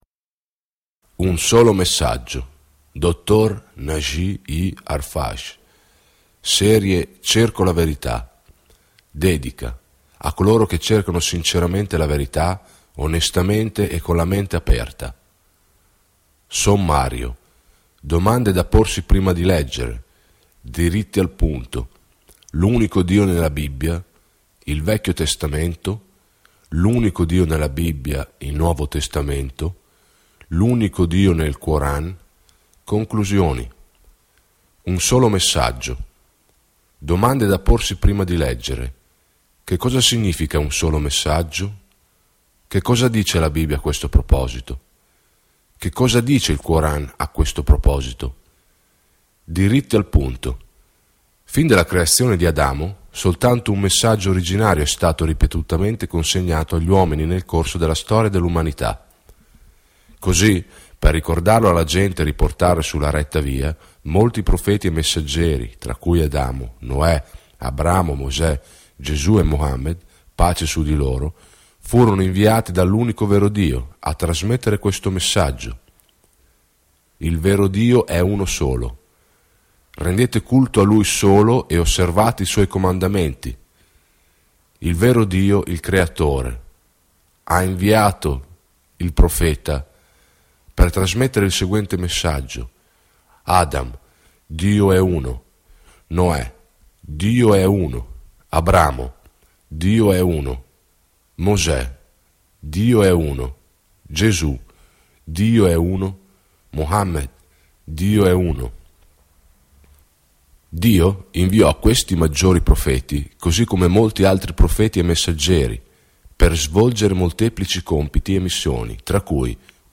You can Litsen or Download it Un Solo Messaggio! è un audiolibro per coloro che cercano la verità con sincerità, onestà e apertura mentale. Dopo la creazione di Adamo, un solo messaggio originale è stato ripetutamente trasmesso all'umanità nel corso della storia.